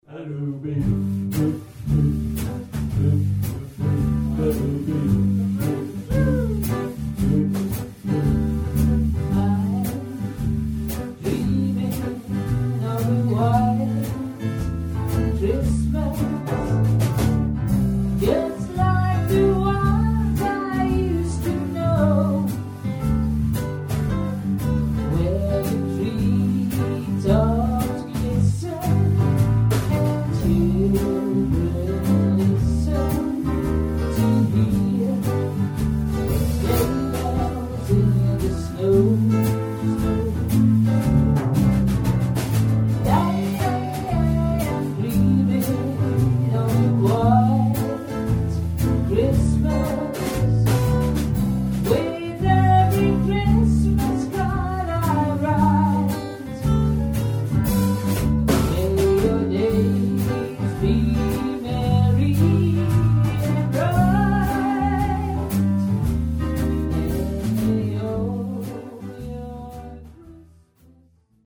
Onderstaande stukje muziek is van een repetitie.(We zijn hier bezig met een wat andere versie van White Christmas)
WhiteChristmas-repetitie.mp3